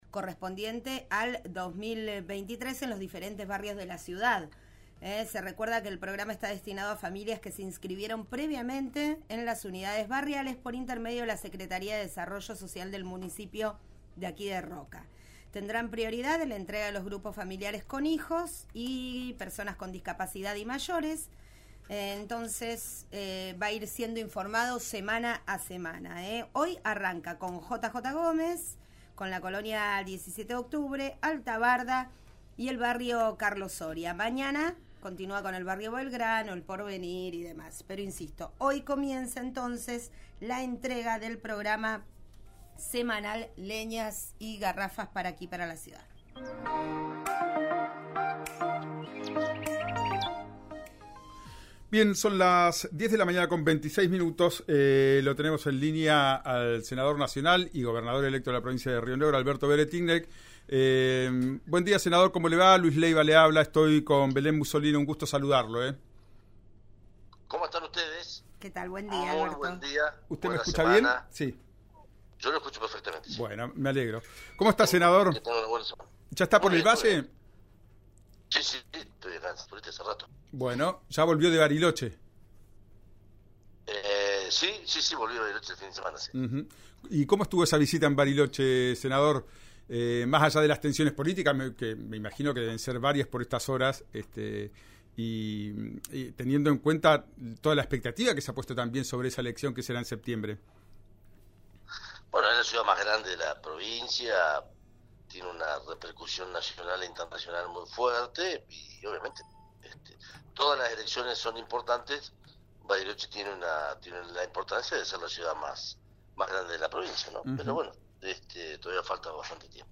El senador y futuro gobernador de Río Negro, Alberto Weretilneck, reflexionó sobre las elecciones municipales del último fin de semana en Cinco Saltos, Dina Huapi y Valcheta. Además, explicó cómo transcurre el proceso previo a las definiciones de candidatos en la ciudad de Bariloche. Por otro lado, en diálogo con RÍO NEGRO RADIO, el líder del partido provincial abordó el tema del conflicto en torno a las represas de Río Negro y Neuquén.